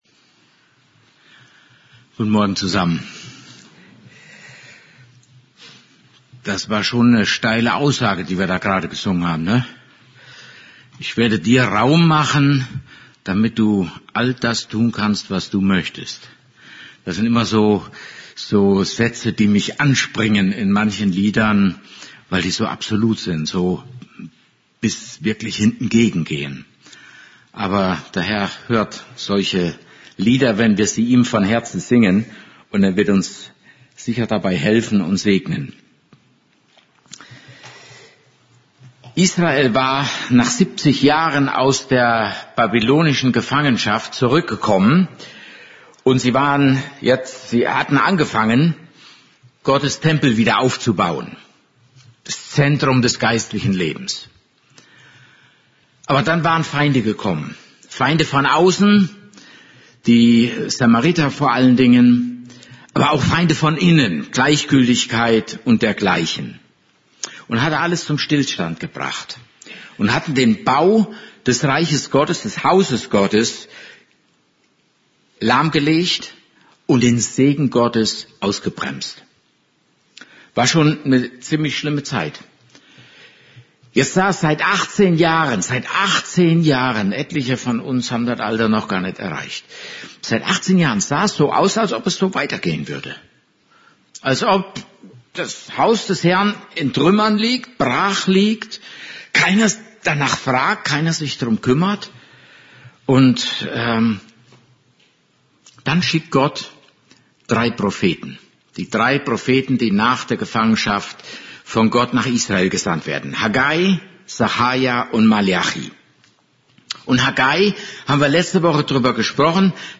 Service Type: Gottesdienst